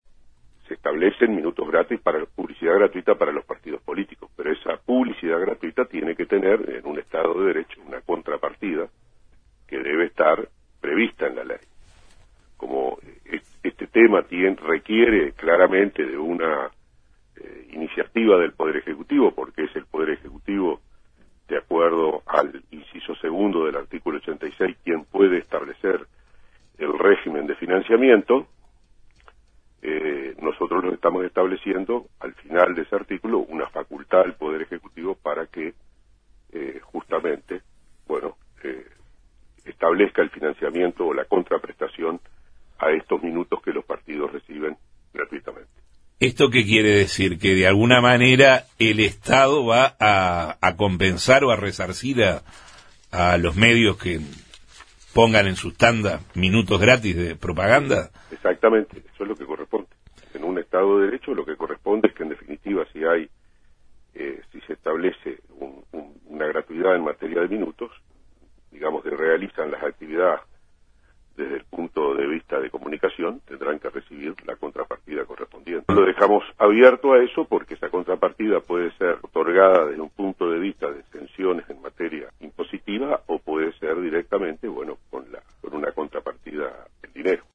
En Justos y pecadores entrevistamos al legislador del Partido Independiente, Iván Posada, sobre los avances en los artículos que integran la ley de financiamiento de partidos políticos en Diputados